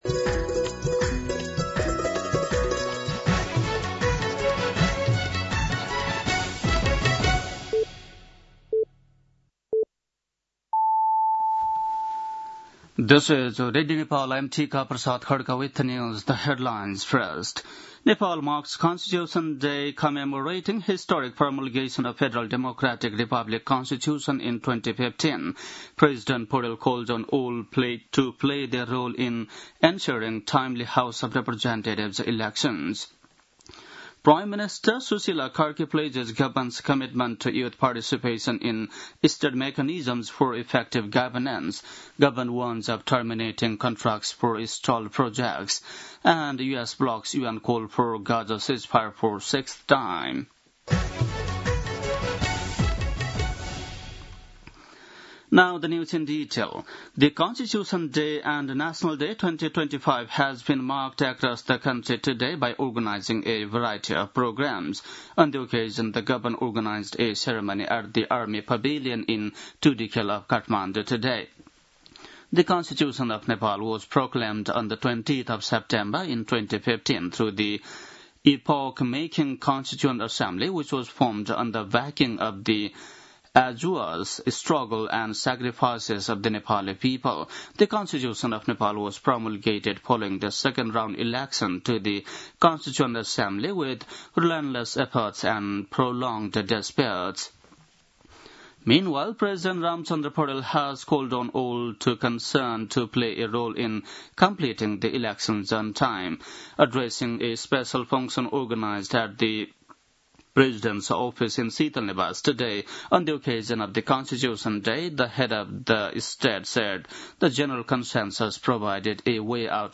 बेलुकी ८ बजेको अङ्ग्रेजी समाचार : ३ असोज , २०८२